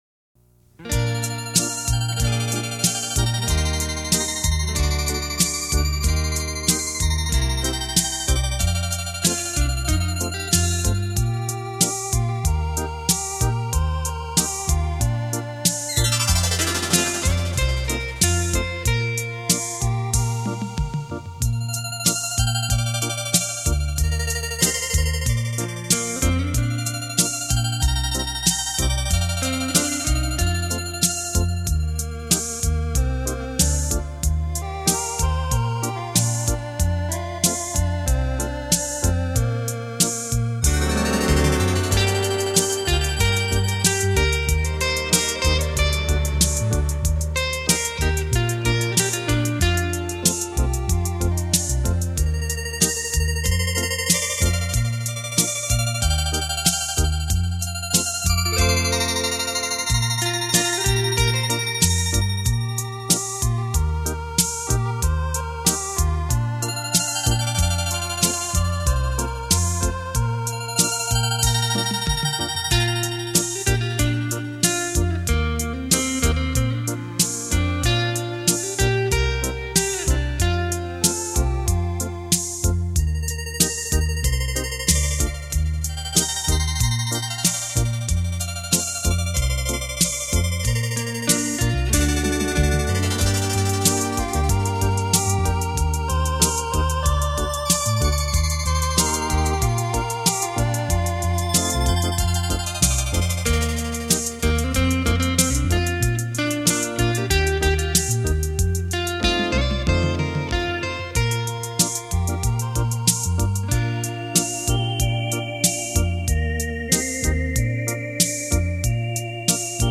无损音乐